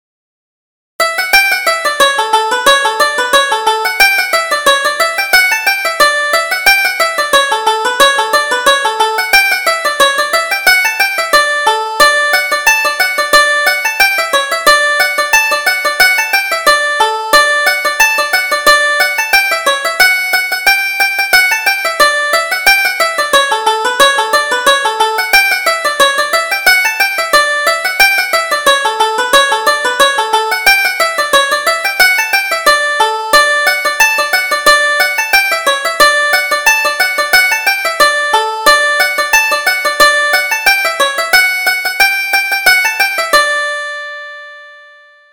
Reel: The Flowing Bowl